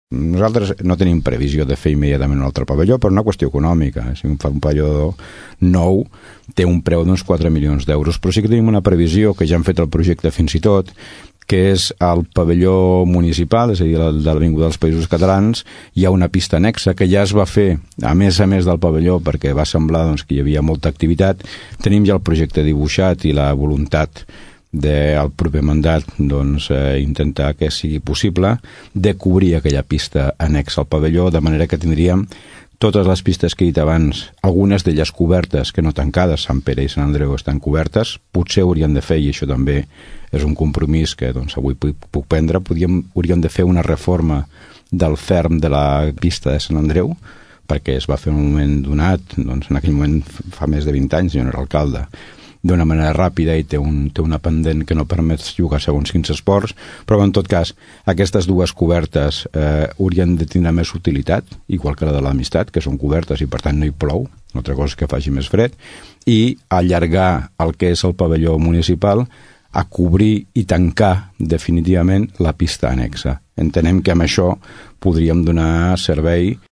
L’Alcalde de Tordera diu que no hi ha previsió de fer un nou pavelló, però sí que està previst cobrir la pista annexa del pavelló poliesportiu municipal. També posa de manifest que caldrien millores al terra de la pista de Sant Andreu.